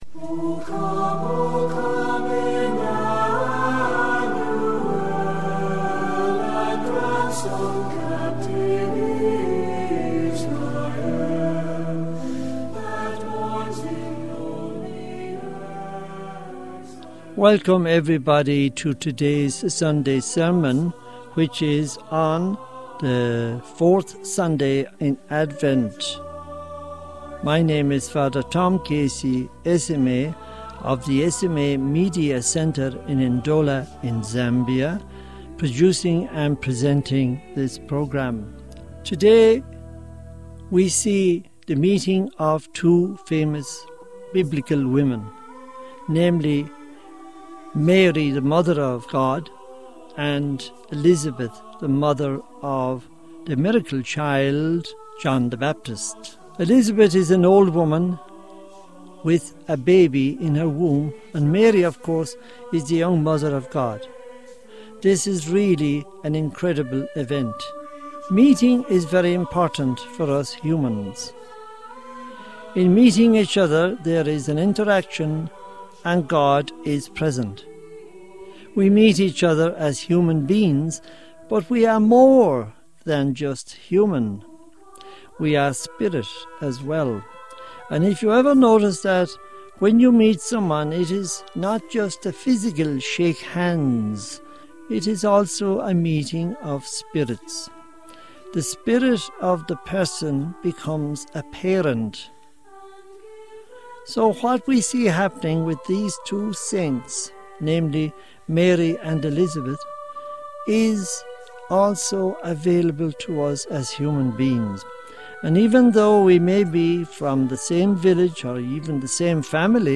Homily for the 4th Sunday of Advent – Year C